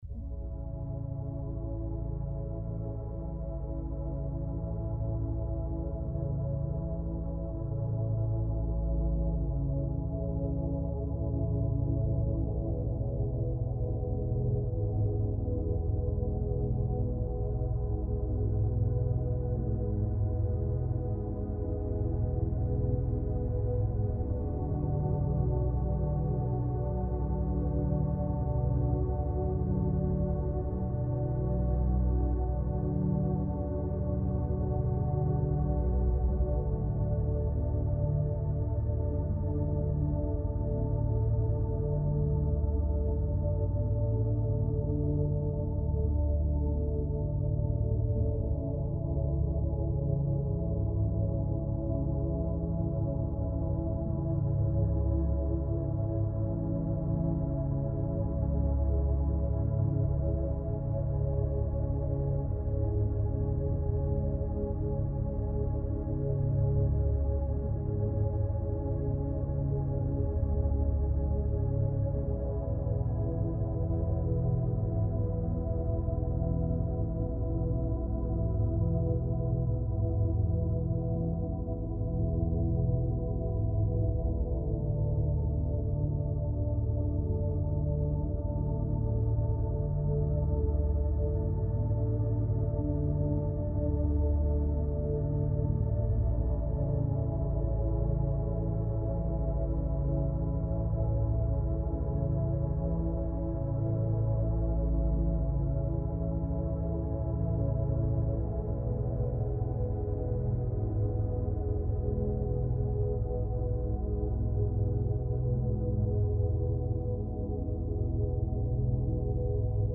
16 hz- Beta Binaural Beats for Concentration ~ Binaural Beats Meditation for Sleep Podcast
Mindfulness and sound healing — woven into every frequency.